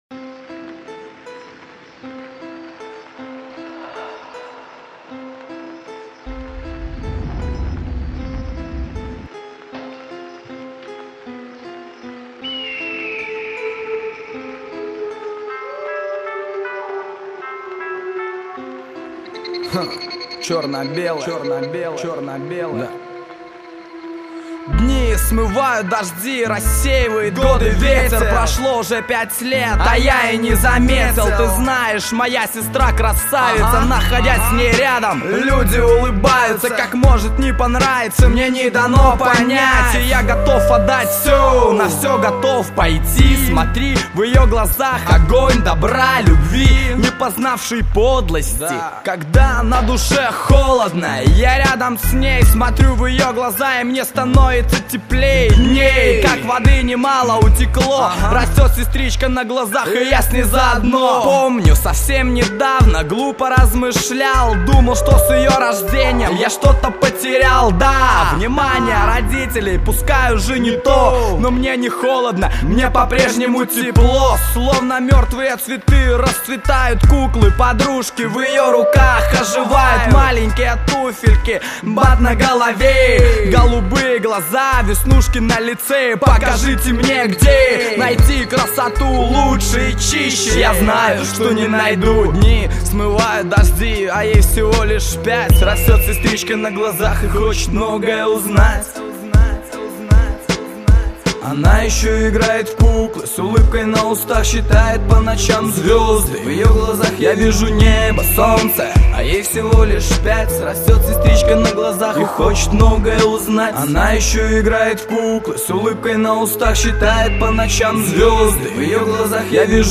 Категория: Hip-Hop - RAP